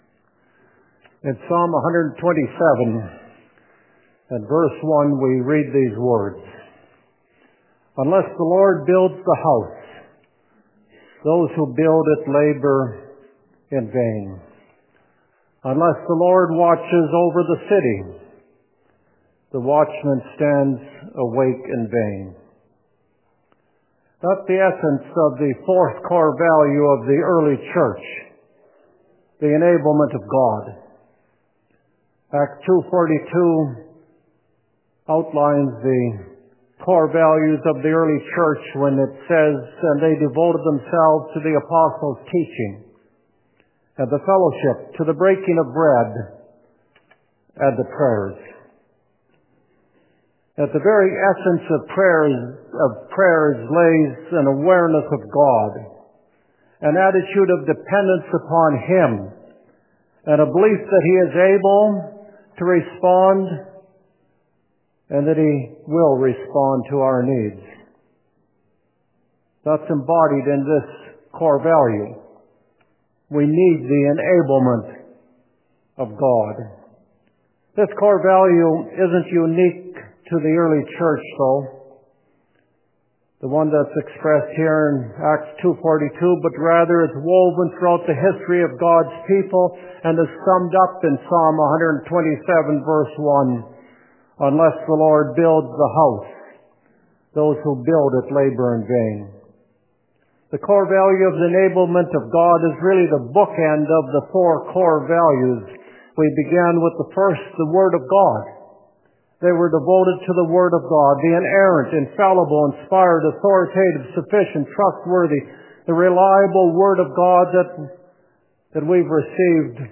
2022 Listen Sermon Outline Part 2 Series No. 8 November 27